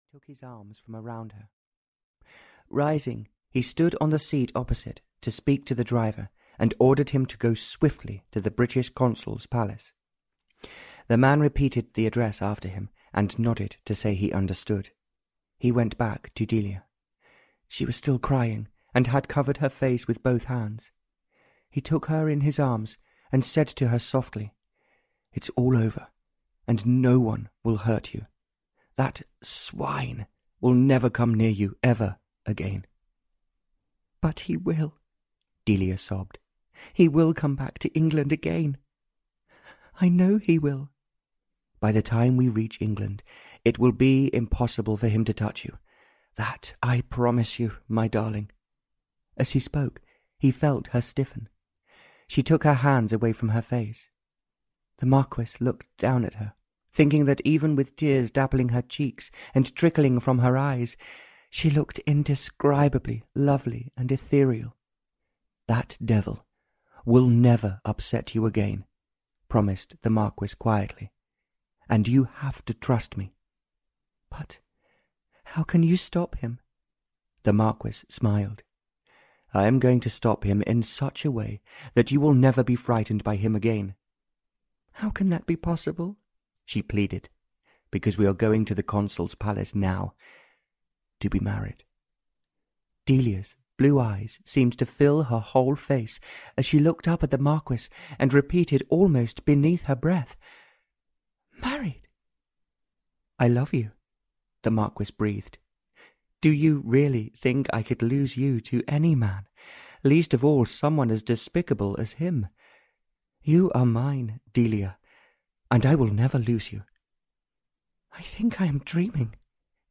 Audio knihaThe Magnificent Marquis (Barbara Cartland's Pink Collection 75) (EN)
Ukázka z knihy